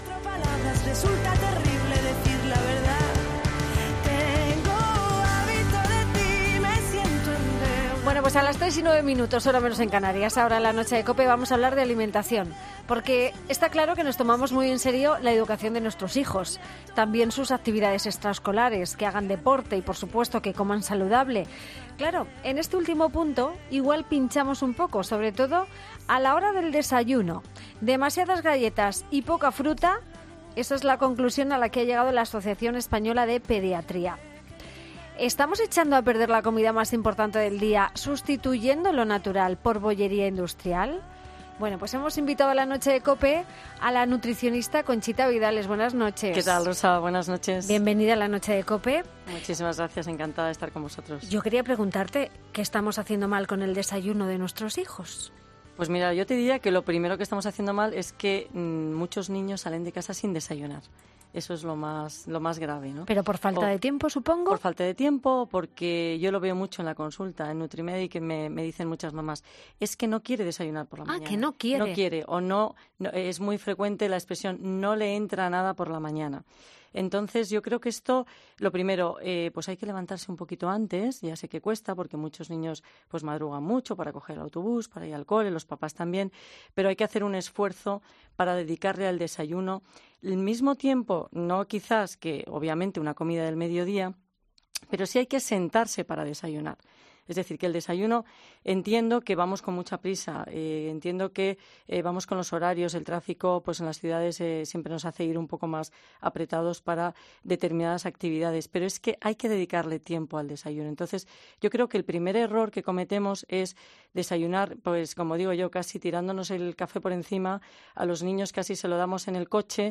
¿Desayunan bien nuestros hijos? Entrevista